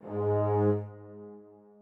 strings2_5.ogg